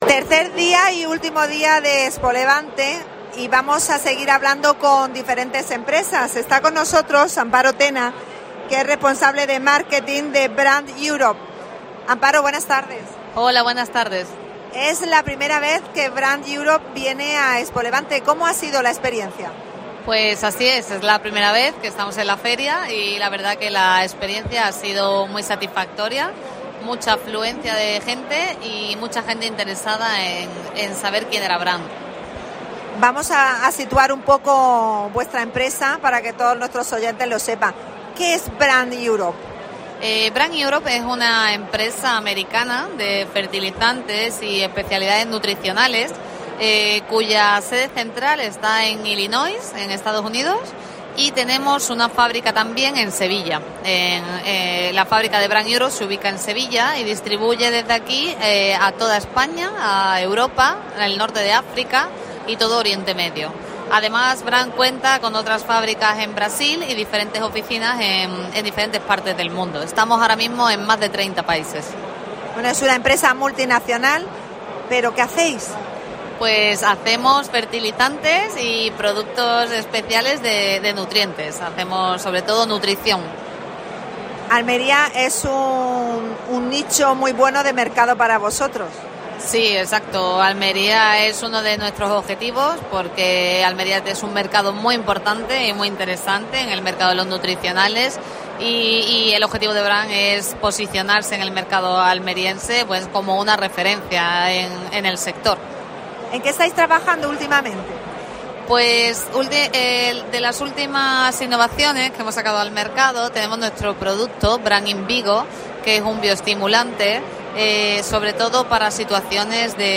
AUDIO: Especial ExpoLevante. Entrevista